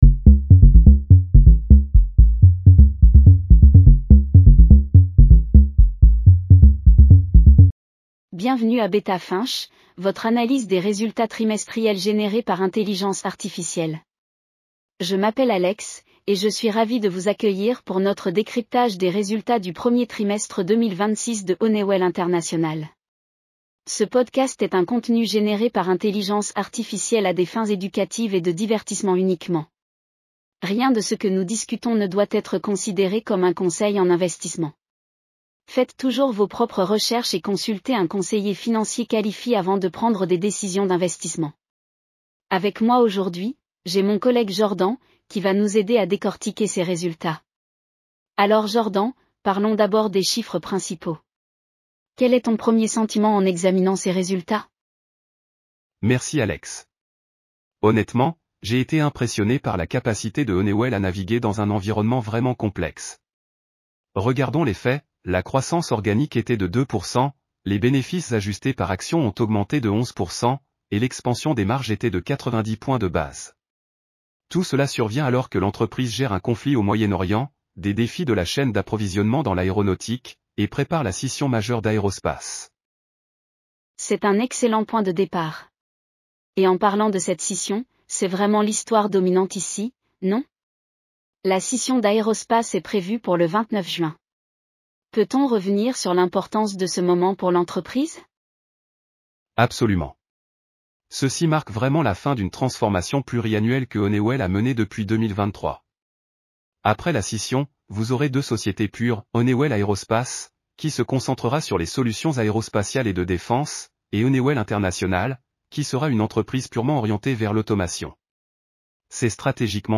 Bienvenue à Beta Finch, votre analyse des résultats trimestriels générée par intelligence artificielle.